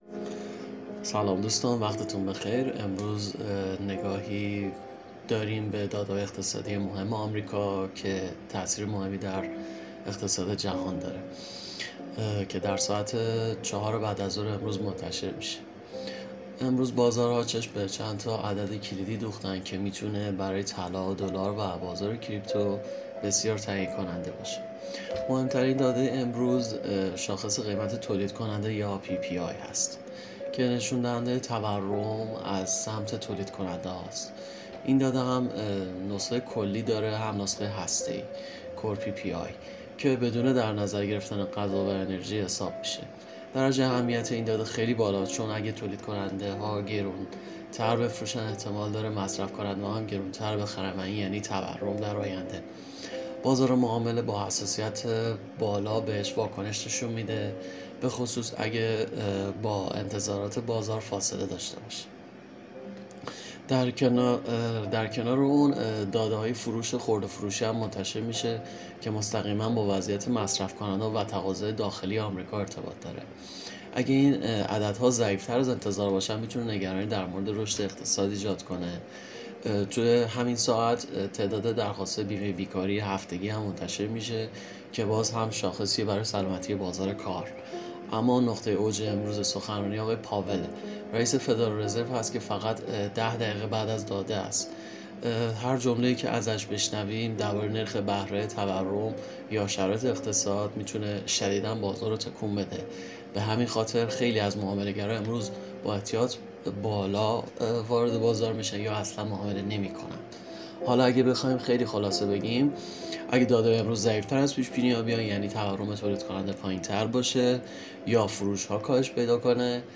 🔸گروه مالی و تحلیلی ایگل با تحلیل‌های صوتی روزانه در خدمت شماست! هدف اصلی این بخش، ارائه تحلیلی جامع و دقیق از مهم‌ترین اخبار اقتصادی و تأثیرات آن‌ها بر بازارهای مالی است.